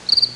Cricket Chirp Sound Effect
Download a high-quality cricket chirp sound effect.
cricket-chirp.mp3